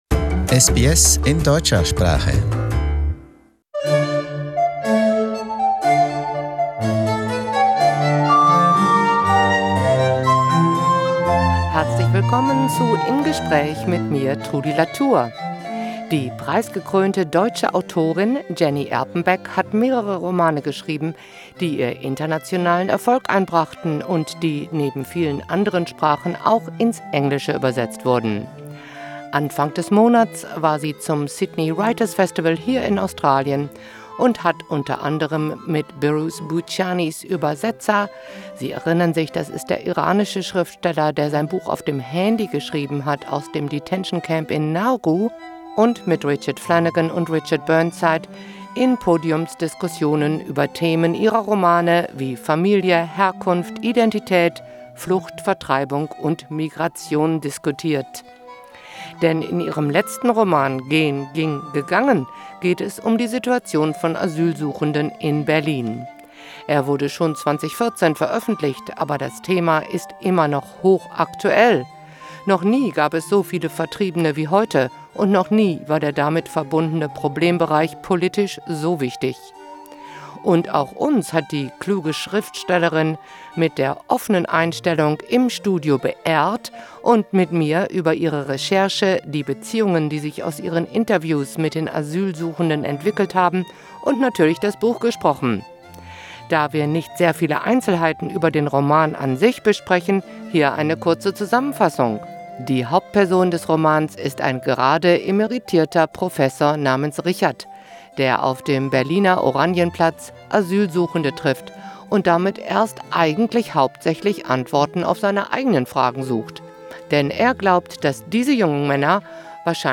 In conversation: Jenny Erpenbeck